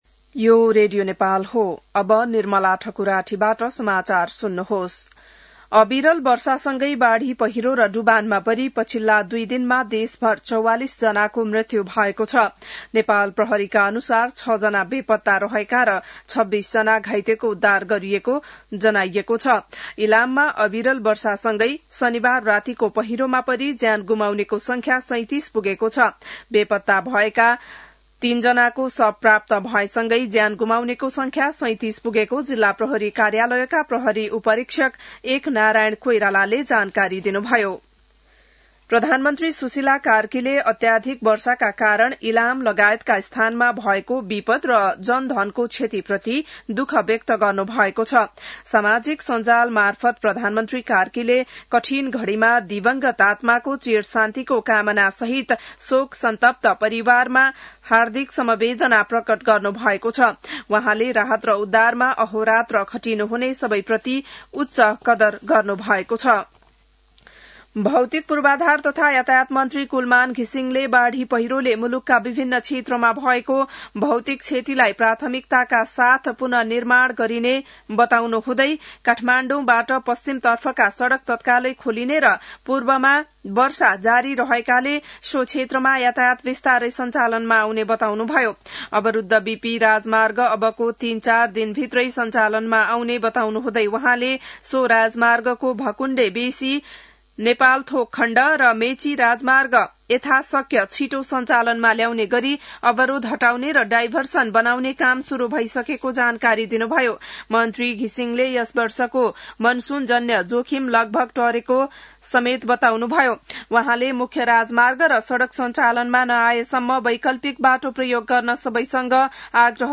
बिहान १० बजेको नेपाली समाचार : २७ जेठ , २०८२